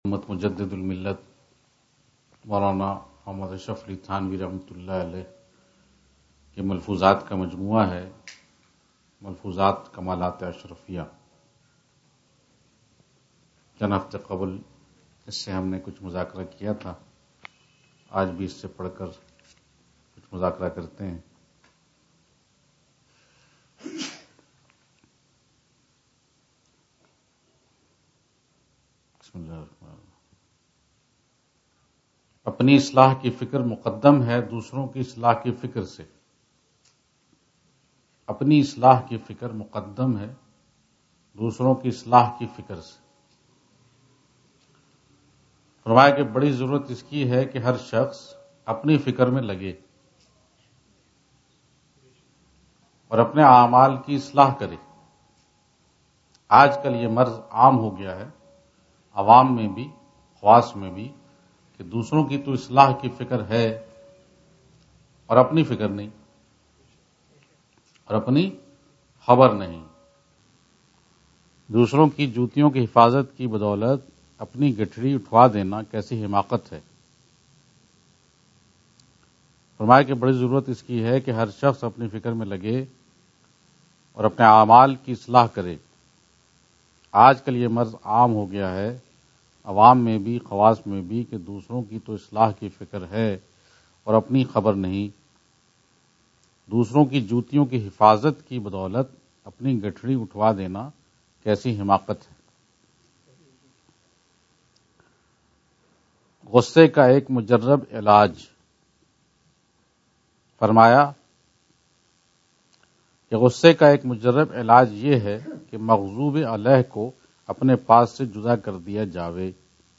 Urdu Bayan – Page 8 – Islah e Nafs
Majlis of April 29